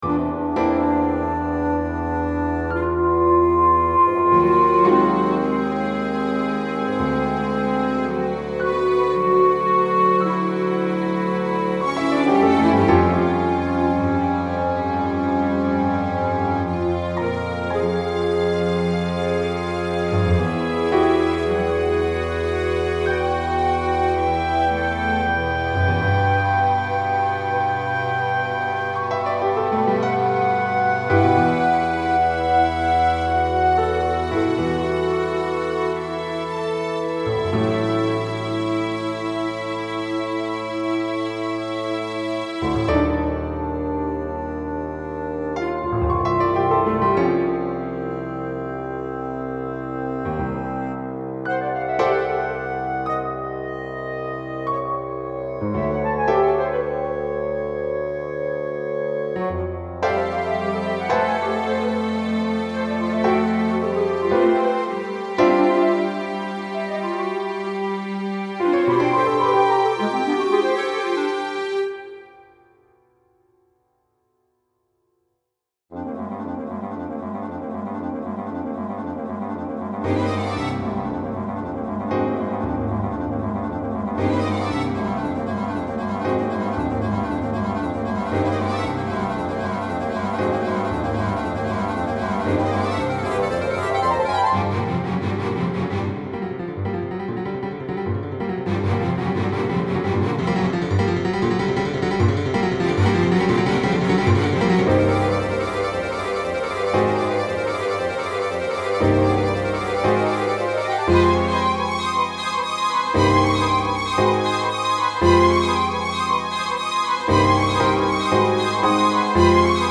2 clr. Bb, bsn, vln, viola, vlc